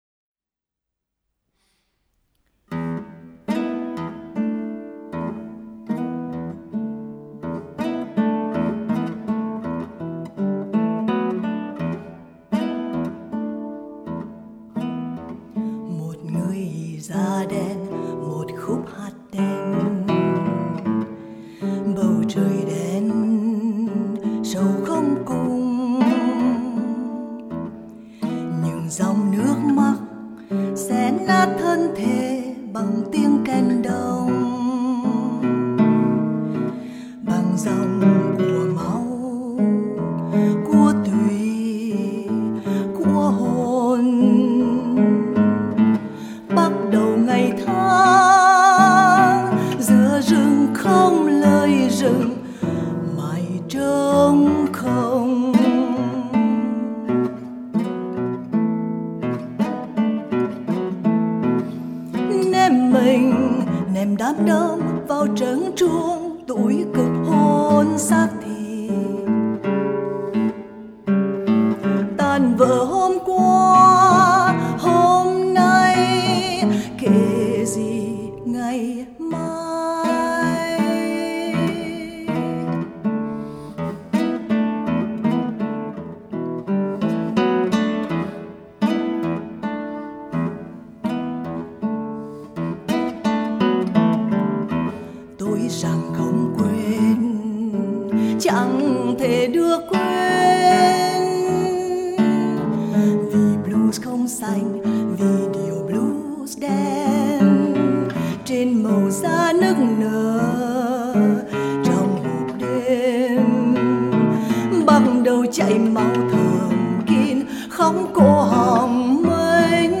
Vietnamesischer Blues